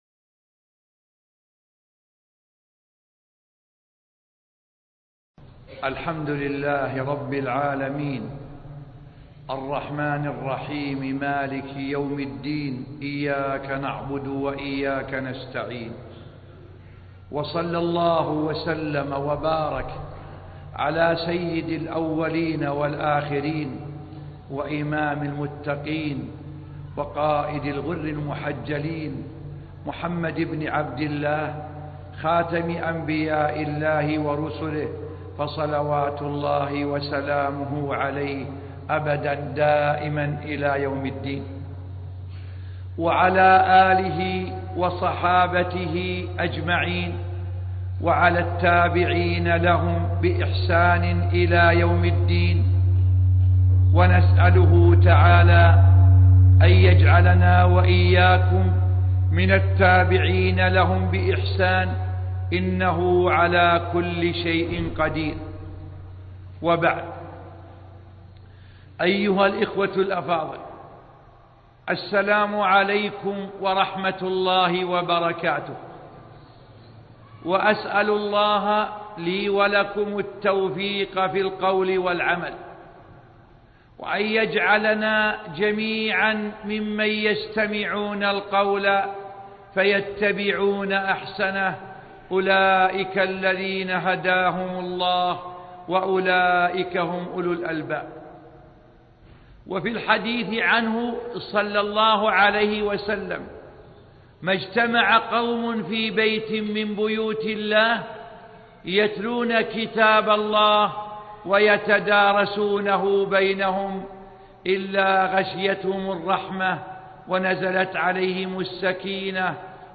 شبكة المعرفة الإسلامية | الدروس | تحقيق الأيمان وأثره في تحقيق ا |عبد العزيز آل الشيخ